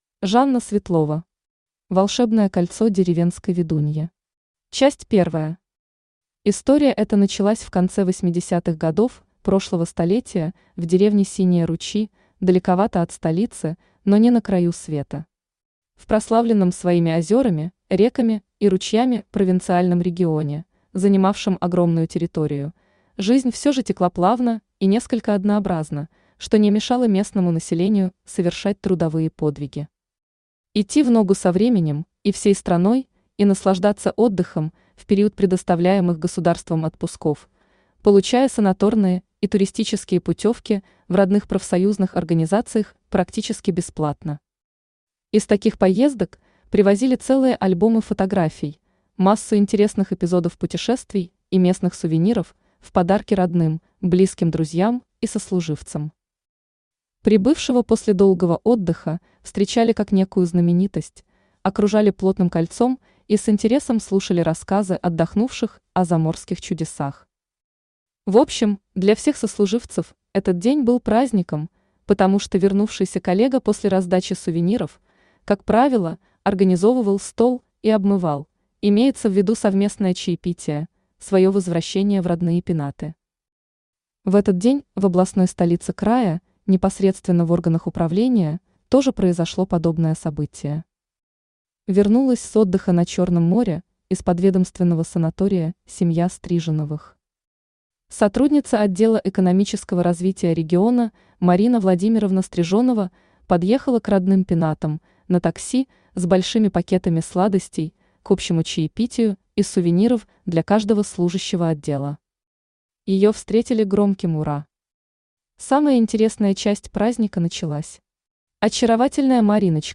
Aудиокнига Волшебное кольцо деревенской ведуньи Автор Жанна Светлова Читает аудиокнигу Авточтец ЛитРес.